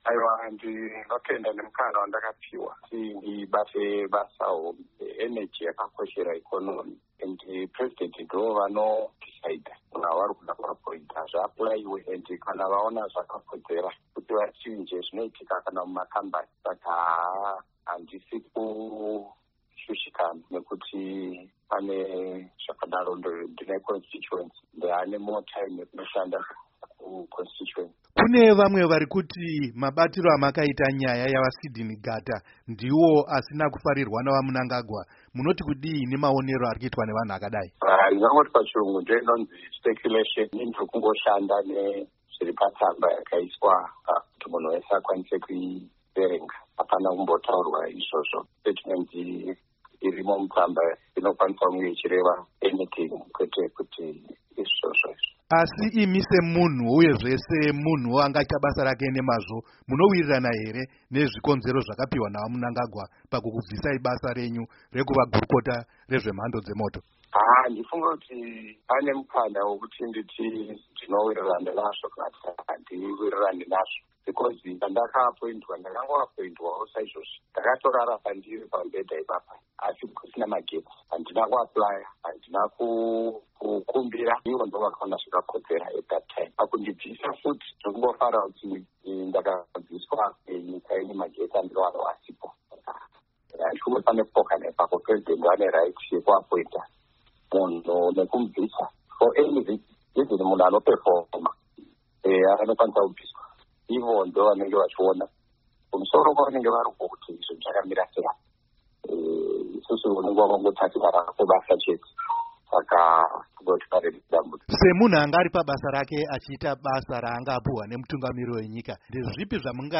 Hurukuro naVaFortune Chasi